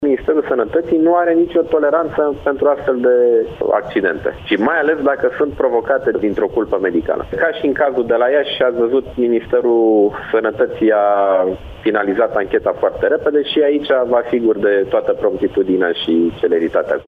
Ministrul Sănătăţii, Nicolae Bănicioiu: